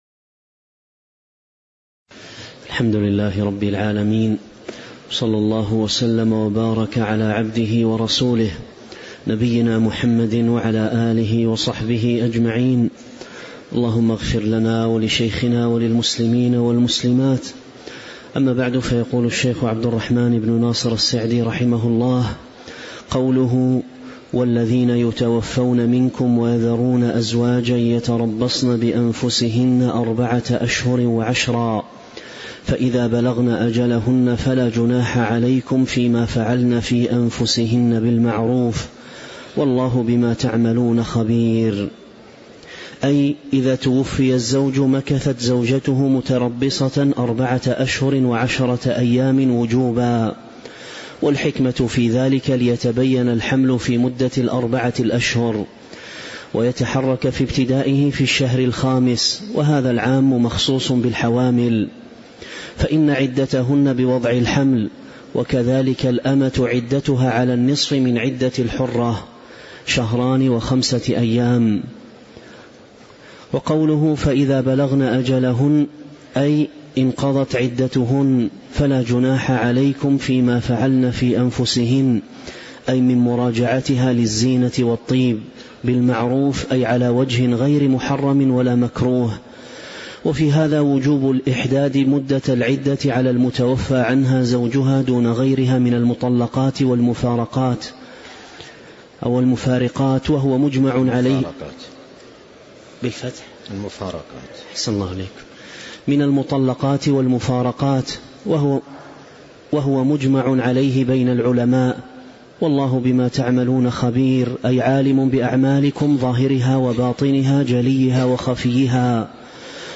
تاريخ النشر ٢٠ شعبان ١٤٤٦ هـ المكان: المسجد النبوي الشيخ: فضيلة الشيخ عبد الرزاق بن عبد المحسن البدر فضيلة الشيخ عبد الرزاق بن عبد المحسن البدر تفسير سورة البقرة من آية 234 (098)